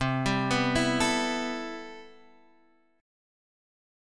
Listen to CM13 strummed